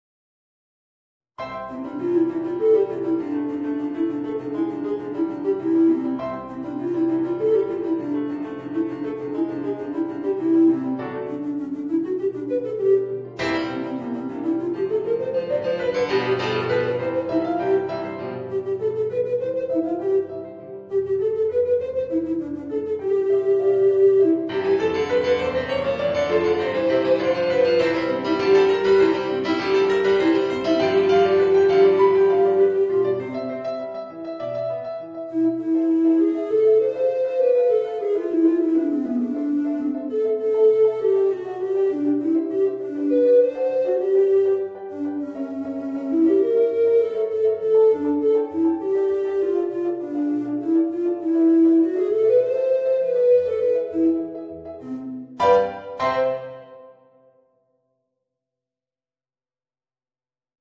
Voicing: Bass Recorder and Organ